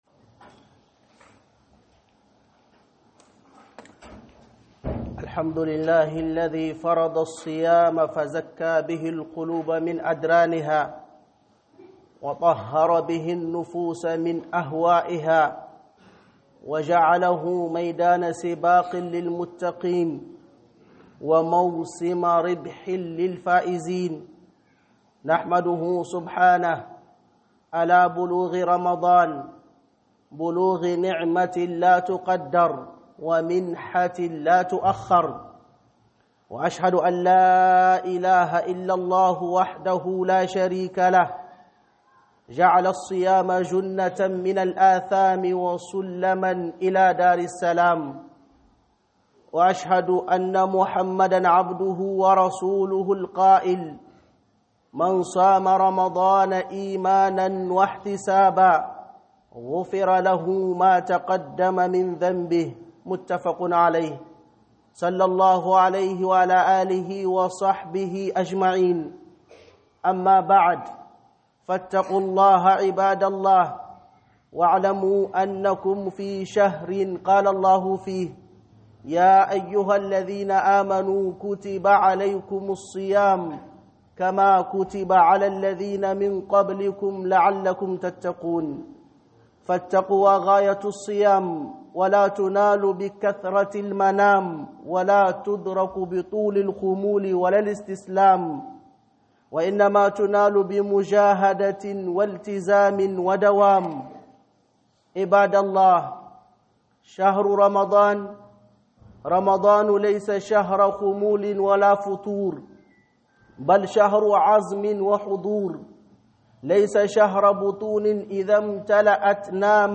- HUƊUBAR JUMA'A